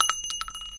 Shell2.ogg